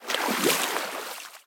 sound / walking / water-08.ogg
water-08.ogg